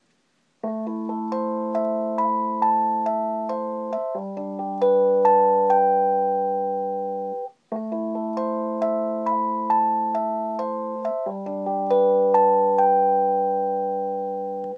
Rhodes + microphone